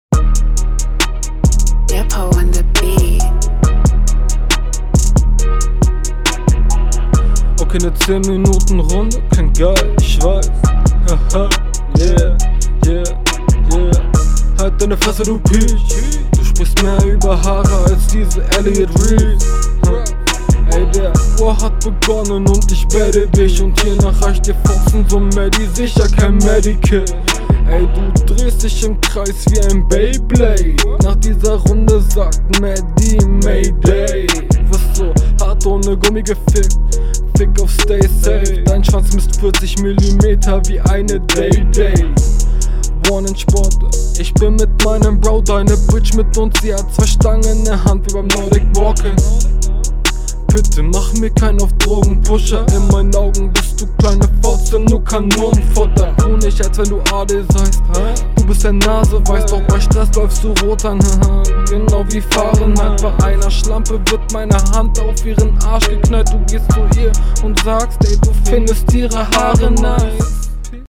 Der Beat ist sehr cool und man versteht dich ganz …
Flow und Taktgefühl ist ganz okay, hier und da ein paar Fehler, aber leider auch …
Ziehst nen freshen Style durch, technisch sauberes Ding, …
Beim Sound gibts bestimmt noch Potentiale, aber mit Kopfhörer war alles gut zu verstehen und …